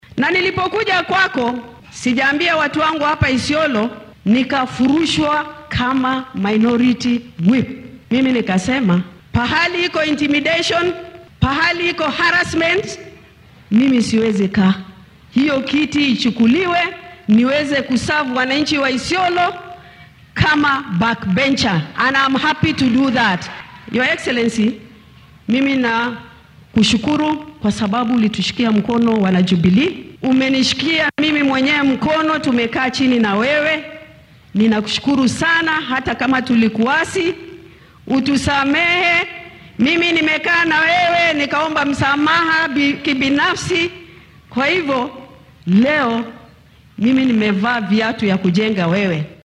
Madaxda ayaa dadweynaha kula hadlay fagaaraha Isiolo Stadium.
Senatarka dowlad deegaankaasi Faadumo Duullo ayaa ka hadashay tallaabadii isbeheysiga Azimio La Umoja-One Kenya uu uga xayuubiyay xilka madaxa kala dambeynta siyaasiyiinta laga tirada badan yahay ee aqalka sare.